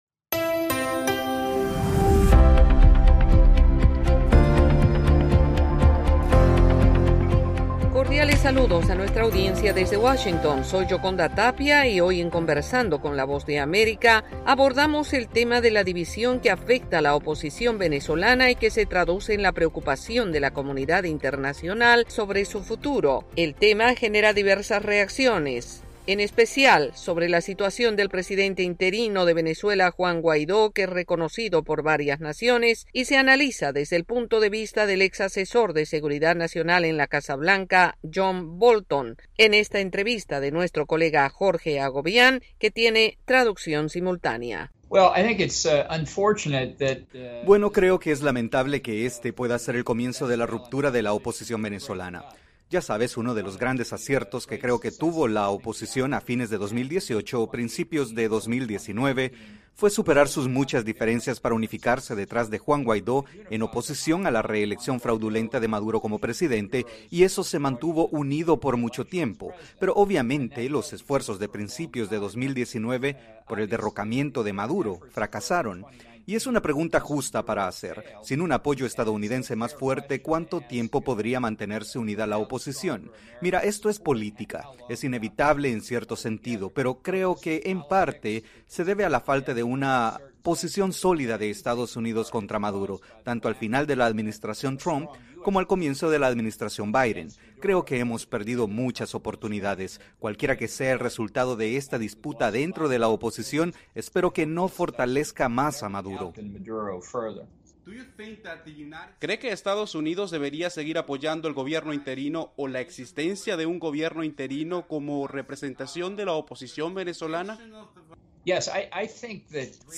Conversamos John Bolton, exasesor de Seguridad Nacional en la Casa Blanca durante la presidencia de Donald Trump, ofreciendo sus opiniones sobre la división de la oposición venezolana apoyado con traducción simultánea.